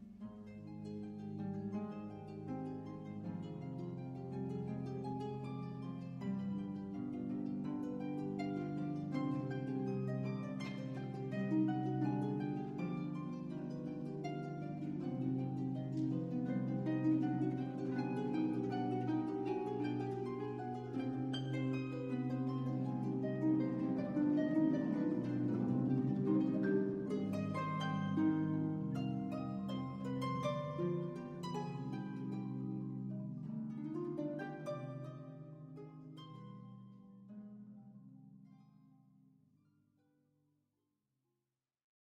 Harp and Marimba
5 octave Marimba, Tam-tam and two bowed crotales (B & F#).